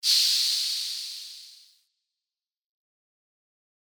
シューン
/ F｜演出・アニメ・心理 / F-80 ｜other 再構成用素材